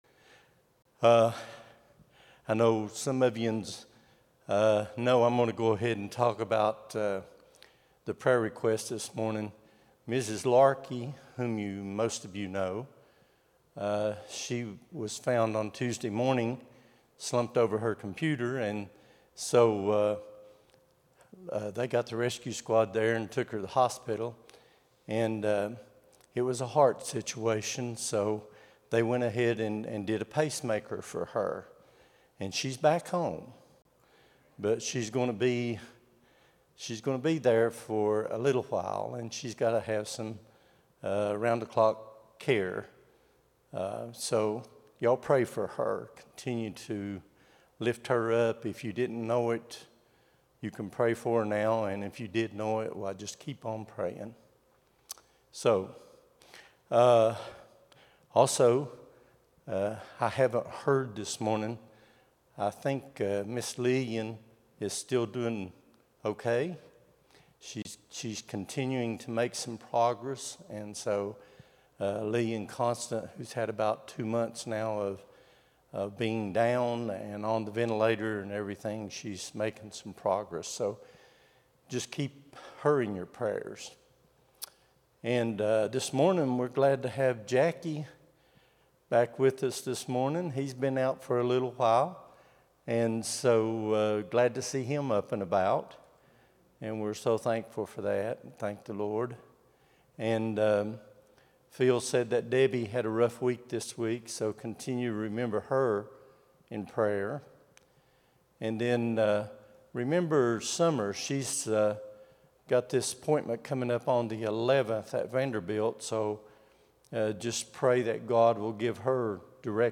08-03-25 Sunday School | Buffalo Ridge Baptist Church